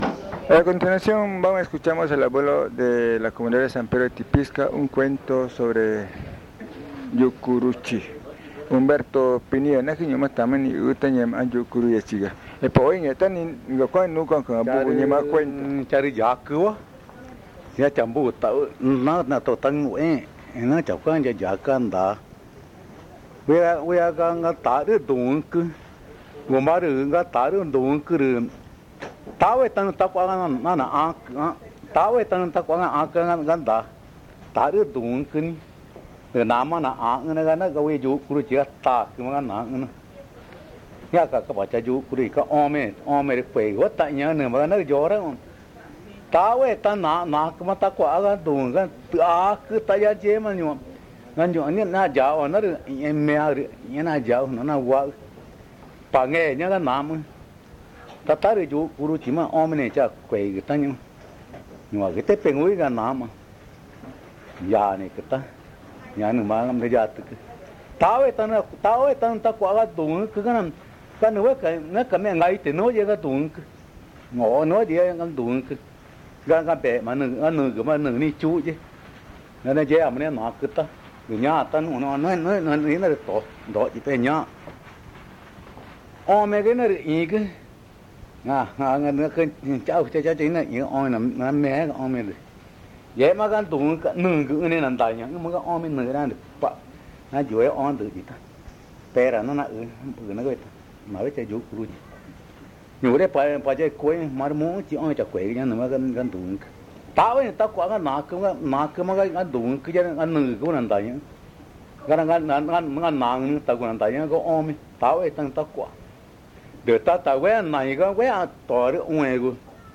San Juan del Socó, río Loretoyacu, Amazonas (Colombia)